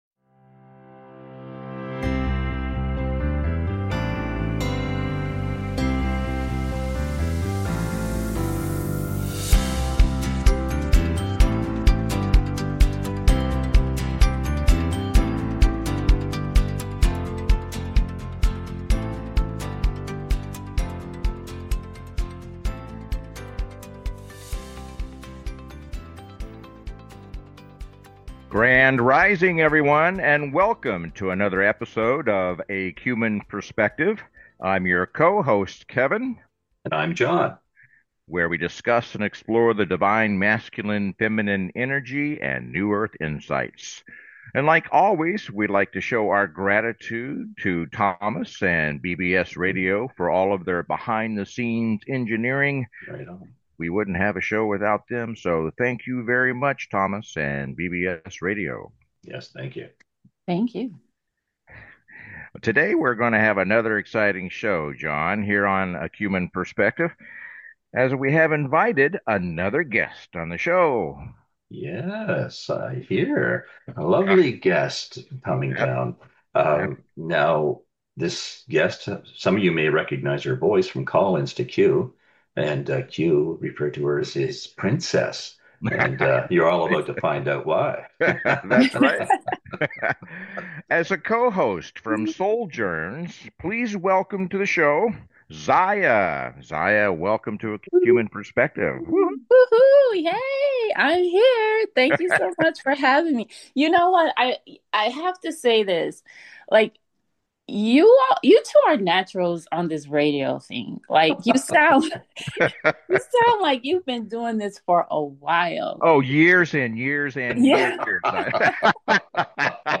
Talk Show Episode, Audio Podcast
The show is structured to welcome call-ins and frequently features special guests, offering a diverse range of perspectives.
The show is not just informative but also entertaining, with humor, jokes, and a whole lot of fun being integral parts of the experience.